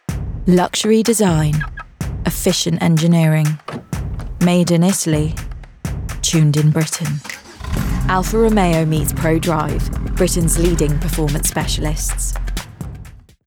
RP ('Received Pronunciation')
Commercial, Cool, Smooth, Confident